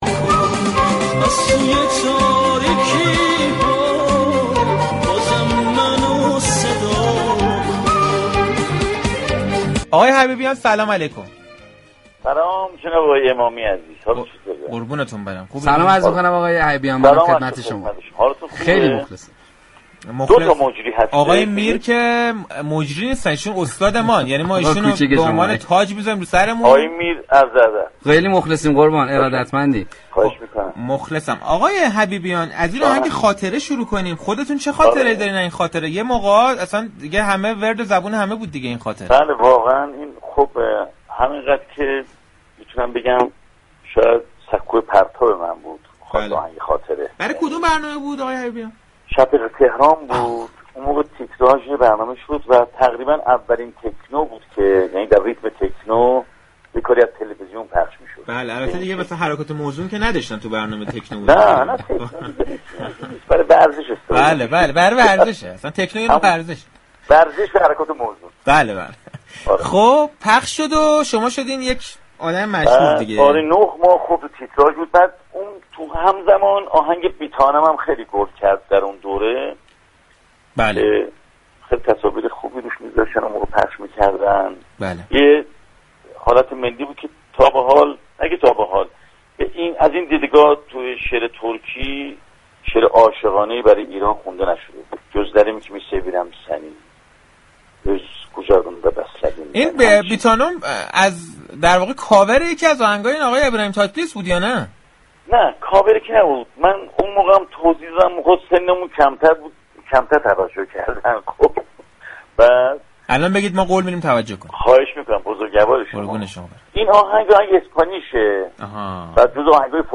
حضور چنگیز حبیبیان، در برنامه‌ی صحنه‌ی رادیو تهران و گفتگو با این خواننده‌ی پاپ سبب شد تا پیامك‌های بسیاری از سوی مخاطبان رادیو تهران به این برنامه‌ ارسال شود كه اجرای زنده‌ی آهنگ